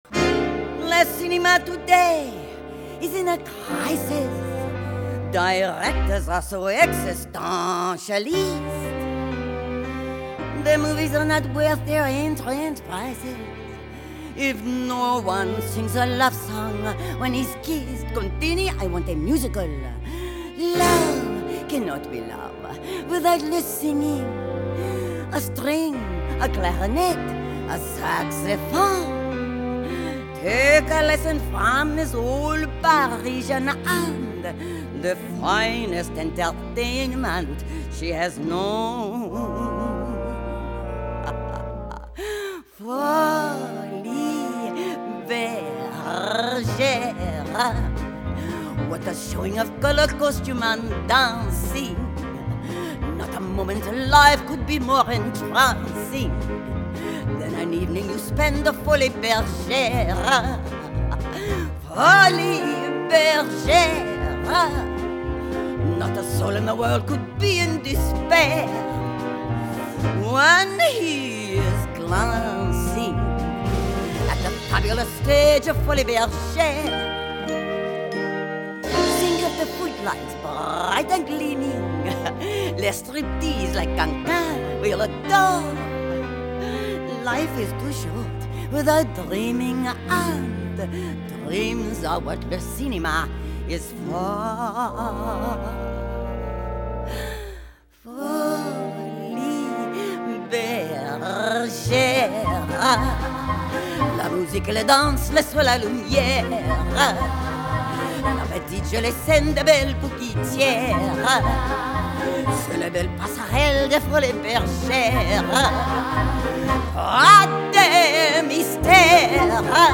Genre: Musical